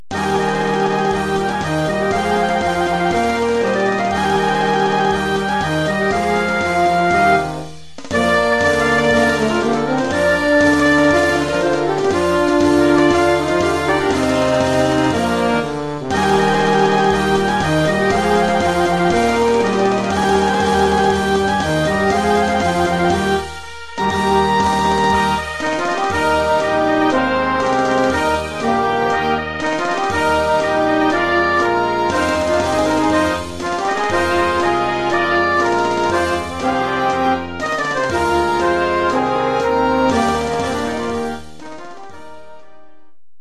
Collection : Harmonie avec Batterie-Fanfare
Marche-pour batterie-fanfare